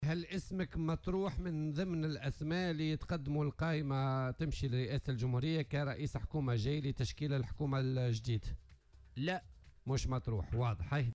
وأكد مرزوق في مداخلة له اليوم في برنامج "بوليتيكا" أن اسمه لم يطرح أبدا ولم يتم اقتراحه لتولي هذا المنصب.